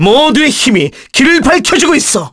Kasel-Vox_Victory_kr.wav